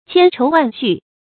千愁万绪 qiān chóu wàn xù
千愁万绪发音